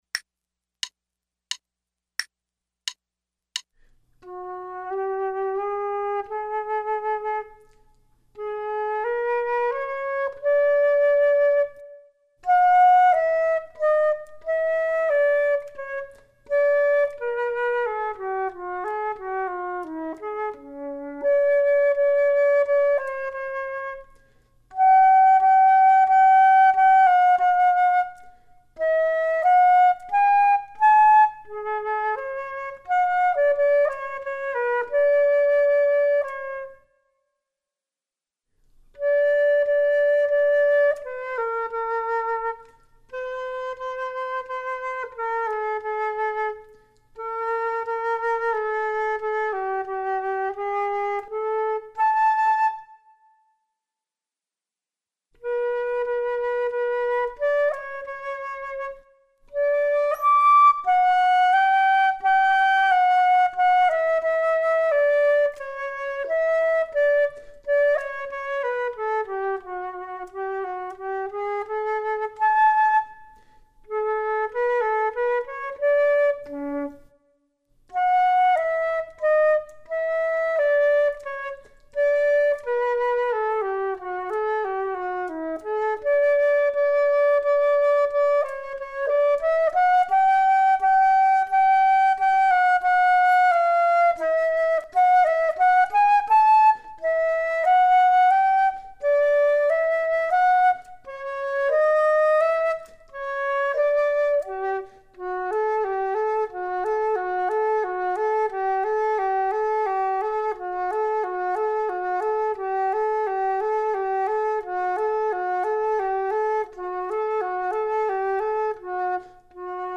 Flute 2 Only:
This piece is a very pretty and understated duet.
This duet presents a good opportunity for the teacher to talk about legato playing, since much of the duet is in a legato style.